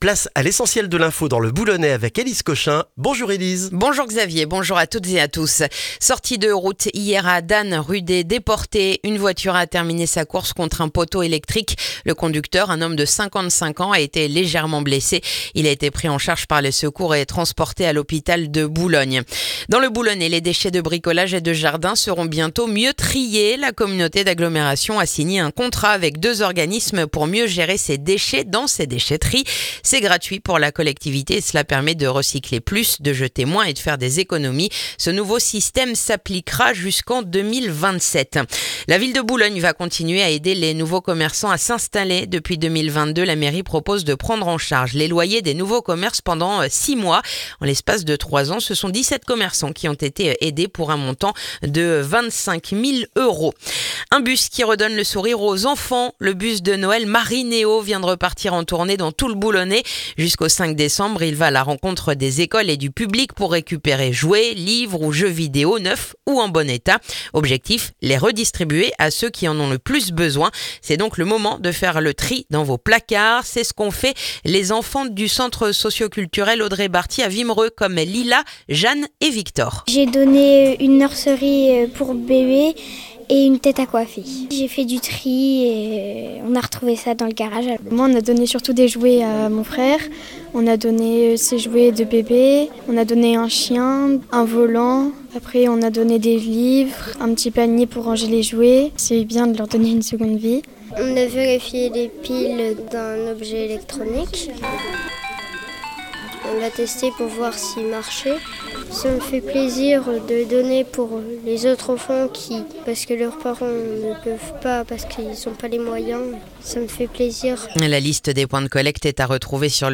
Le journal du vendredi 28 novembre dans le boulonnais